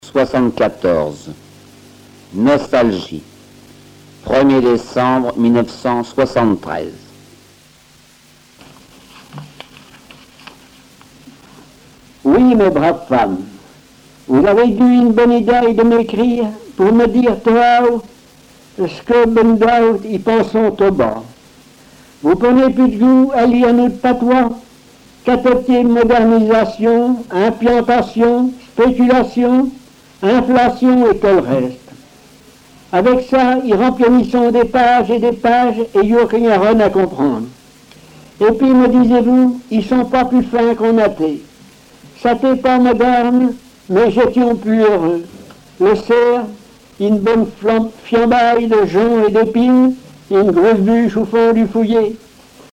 Langue Patois local
Genre récit
Récits en patois